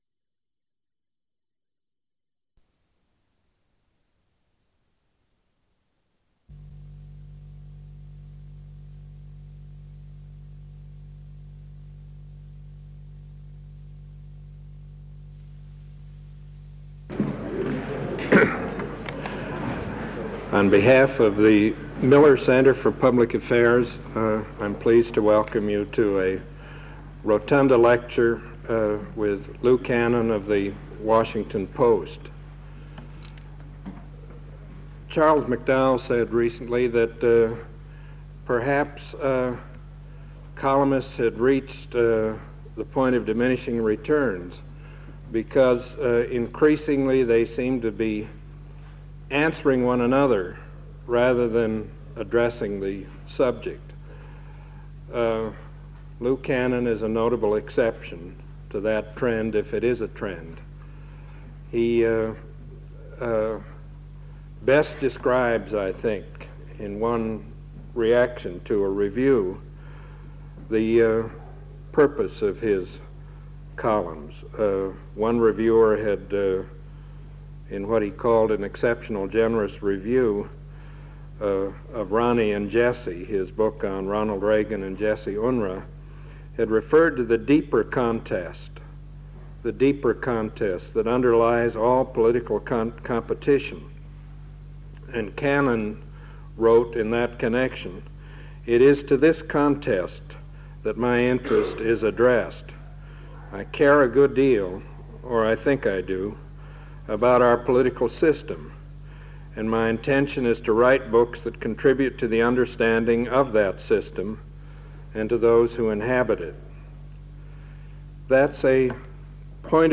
The speaker also discusses Reagan's ability to project an image of leadership and his strategies of communication. During the questioning time, he addresses Reagan's changing relationship to some of his staff and Cabinet members, relationship between Reagan's realism and U.S. foreign policy in Latin America, Reagan's support in Congress, his policy